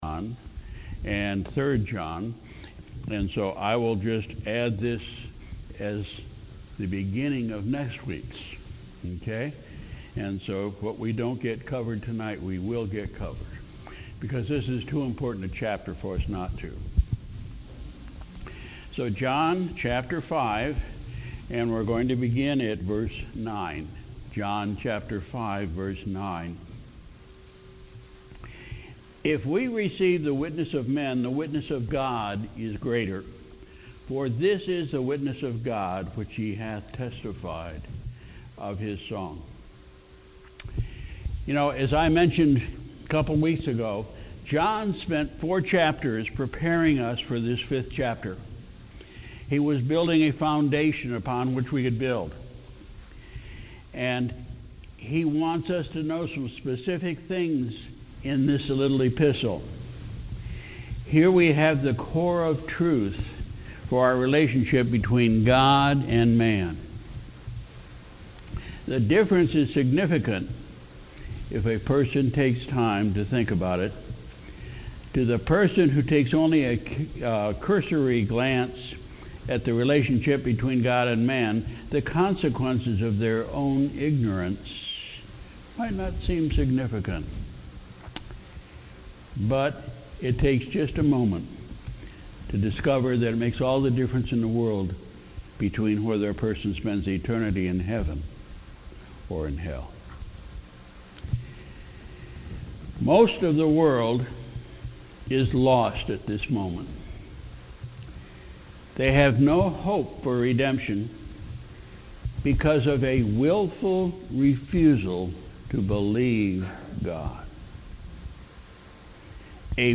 Sunday, November 25, 2018 – Evening Service